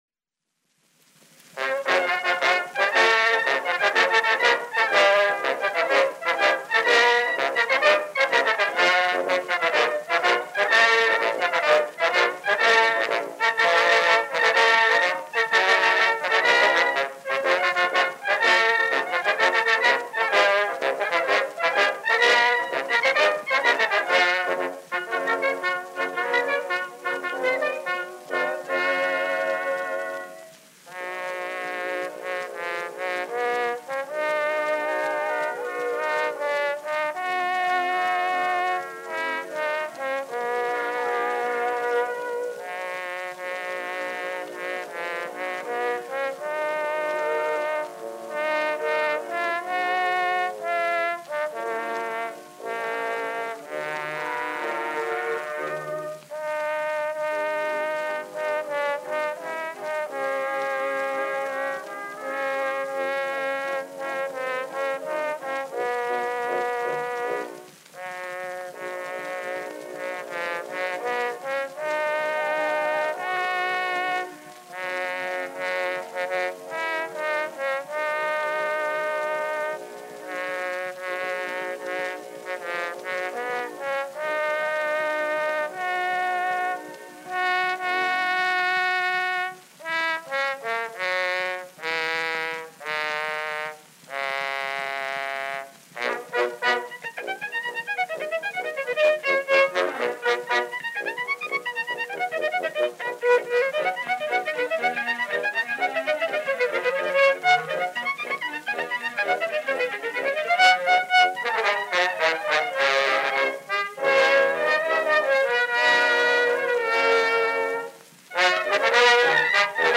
Our collection is comprised of wax cylinder donations from many sources, and with the digitization process being fully automated, not all listed contents have been confirmed.